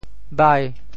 “郿”字用潮州话怎么说？
潮州发音 潮州 bhai5